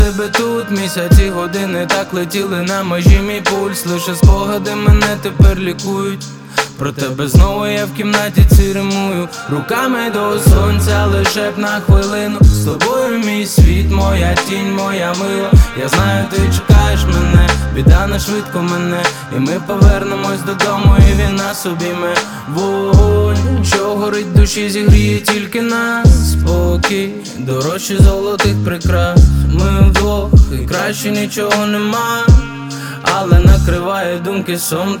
Жанр: Фанк